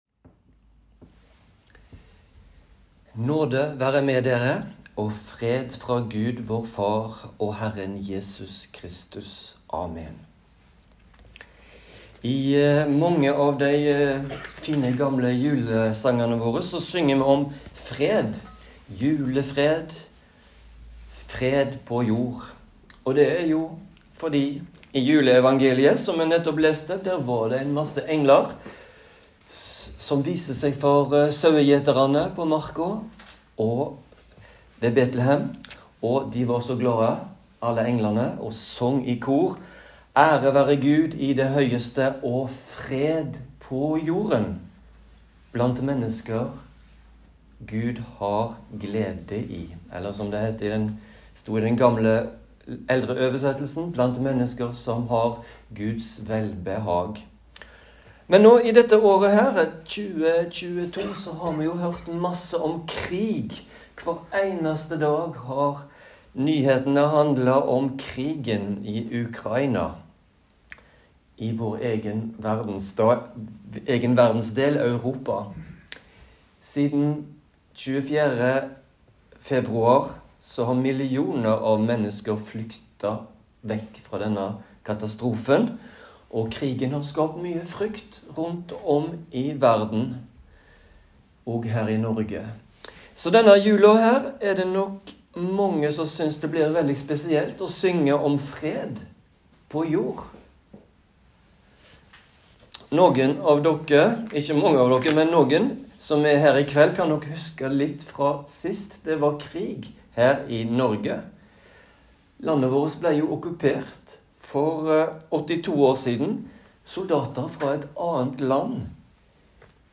Julaftenpreken